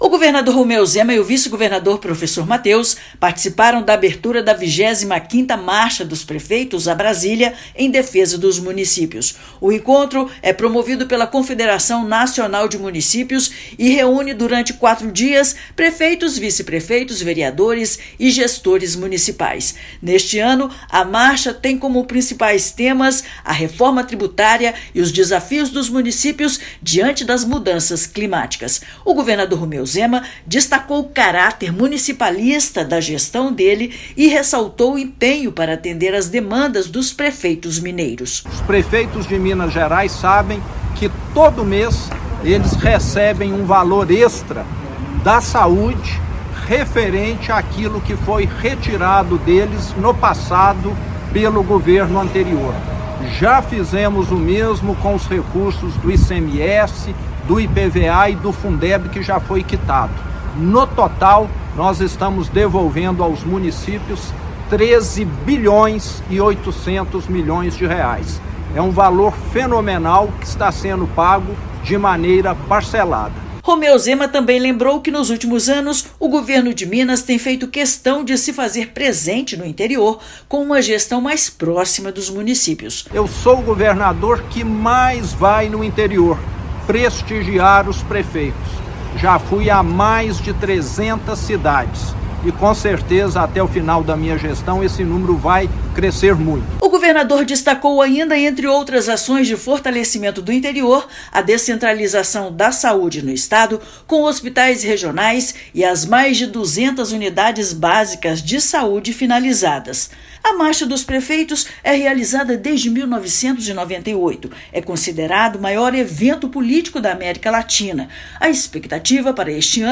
Evento reúne gestores municipais de todo o país no debate das políticas públicas que impactam a vida nas cidades brasileiras. Ouça matéria de rádio.